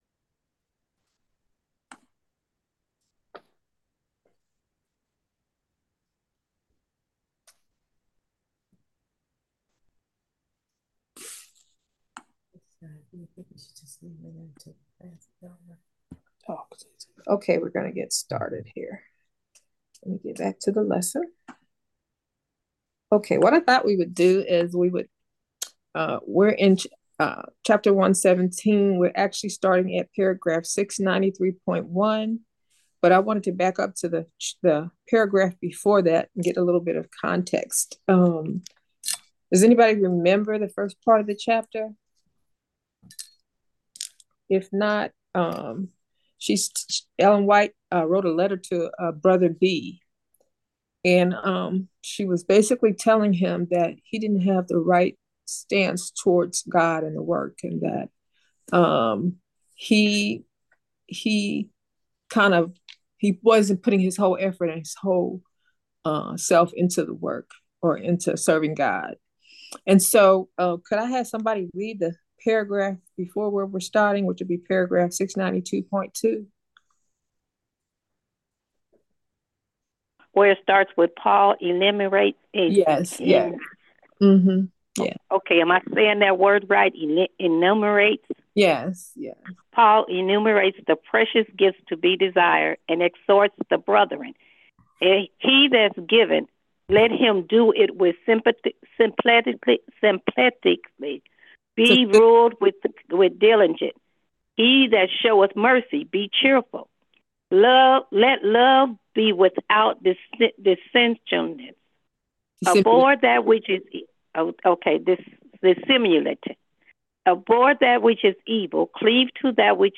Discipleship Class